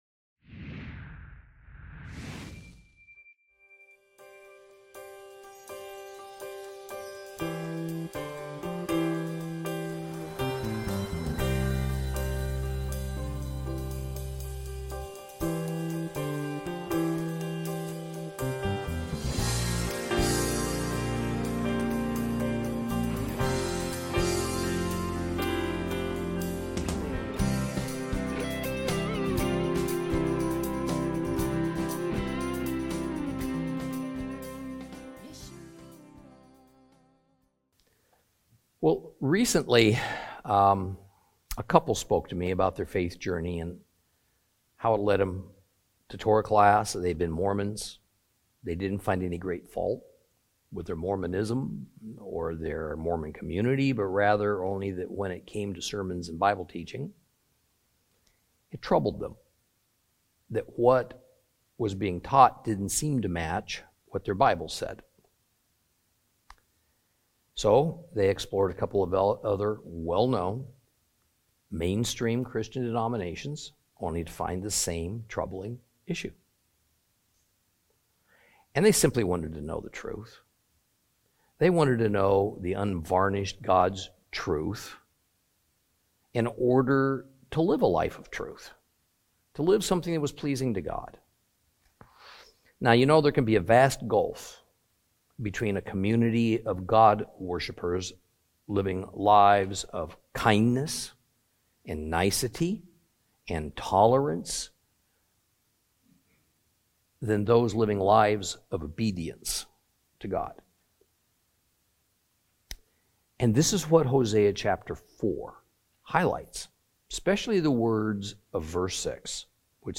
Teaching from the book of Hosea, Lesson 8 Chapters 4 and 5.